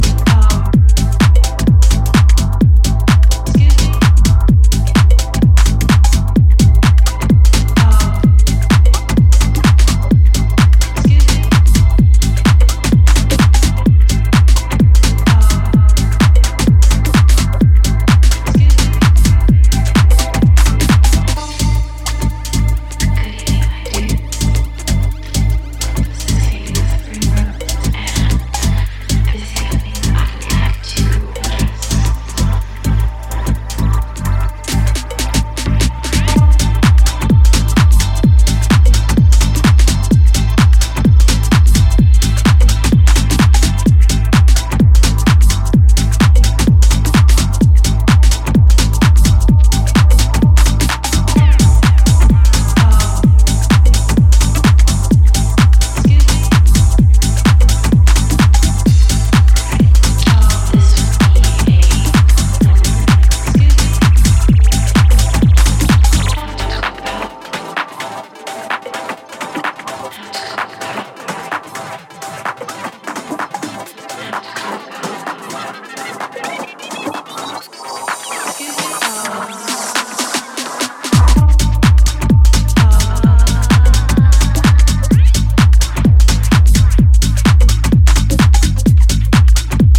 One of the UK’s most exciting house talents